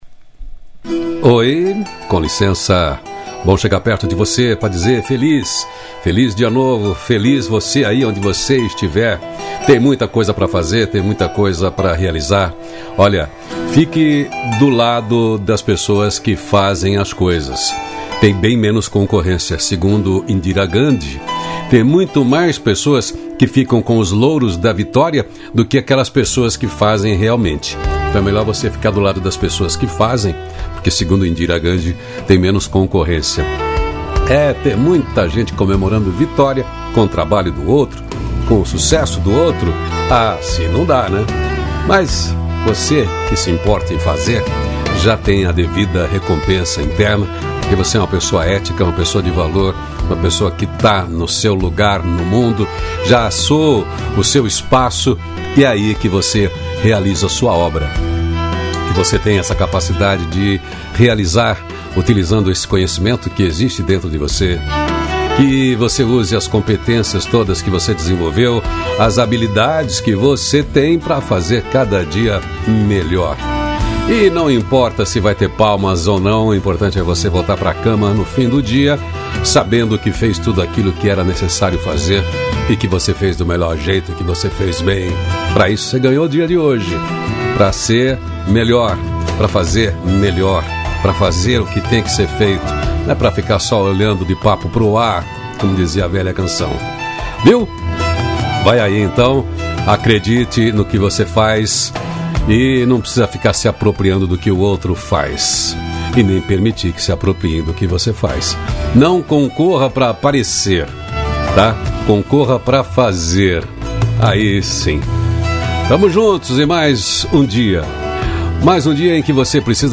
CRÉDITOS: Produção e Edição: Onion Mídia Trilha Sonora: “Between the Shadows” Loreena Mckennitt